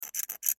Звуки скрежета зубами и царапанья пластиковой штукой